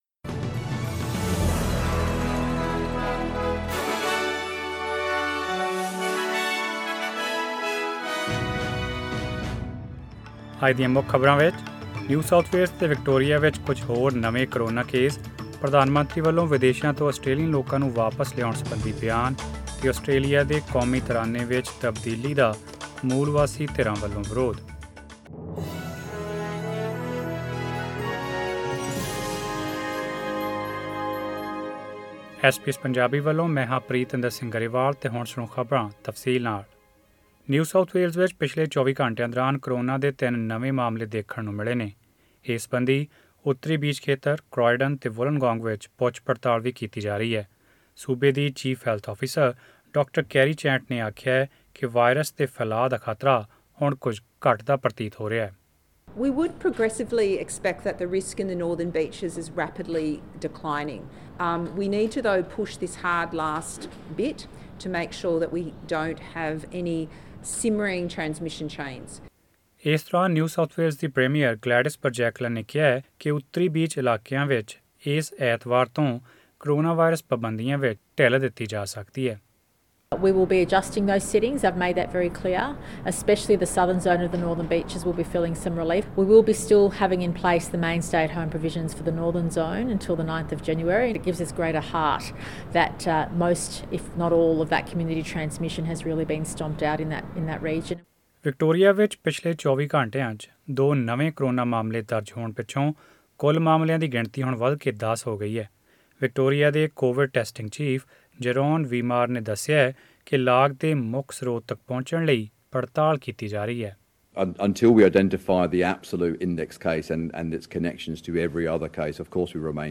Australian News in Punjabi: 1 January 2021